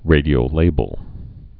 (rādē-ō-lābəl)